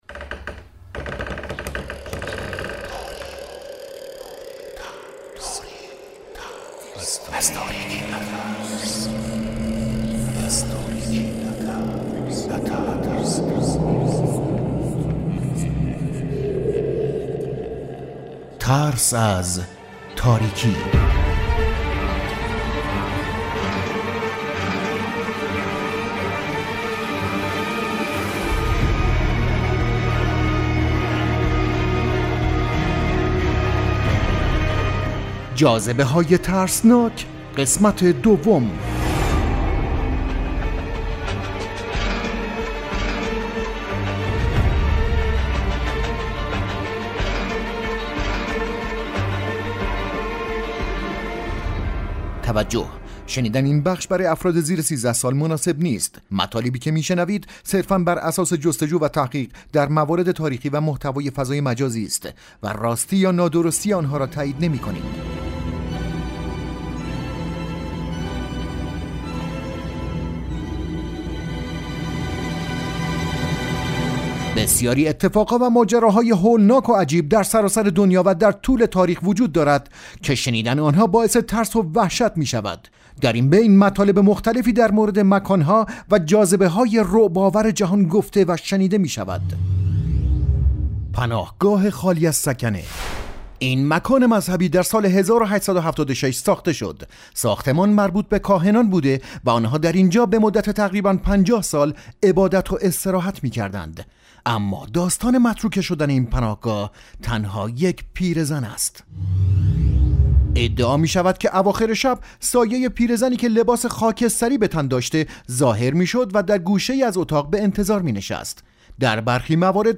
آیتم رادیویی و پادکست